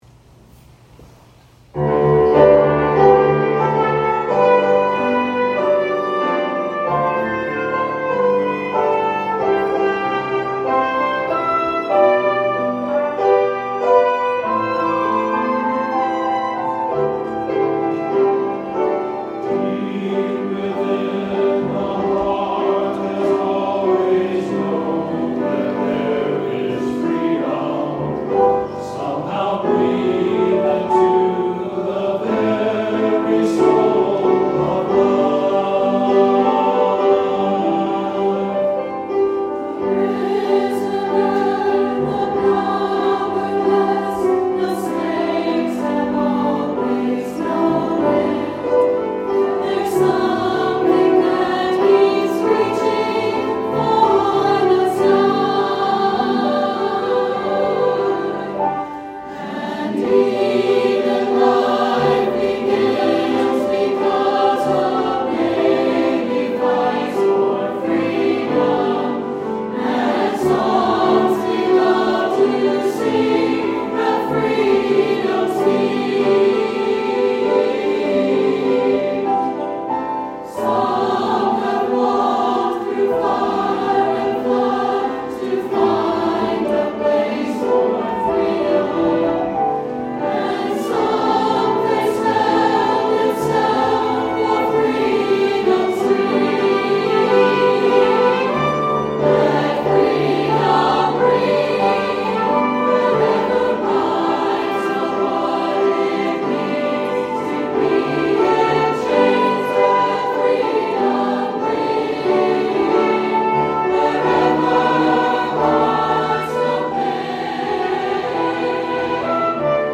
Offertory: Annual Trinity Patriotic Choir